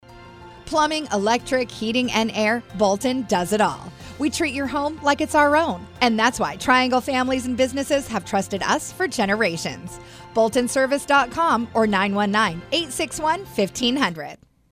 As Heard on Capitol Broadcasting